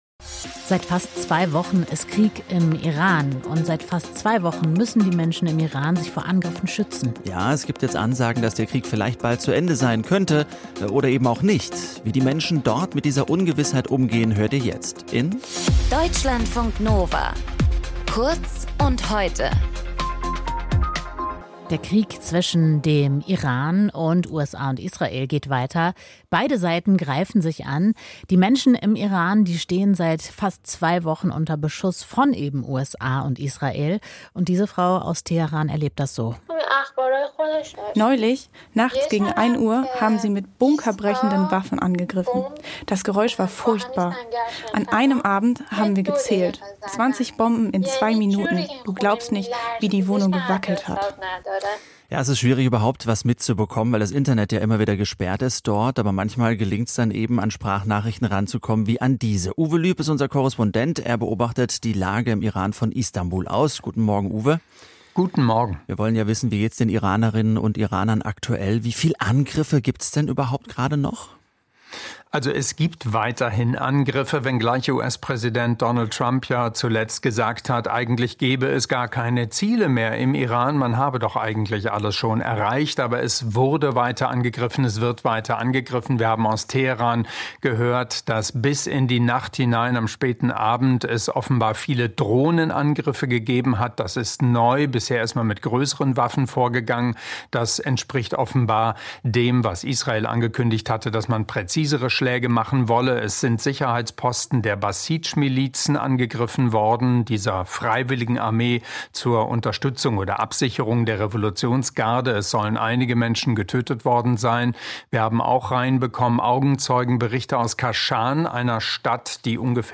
In dieser Folge mit:
Moderation
Gesprächspartner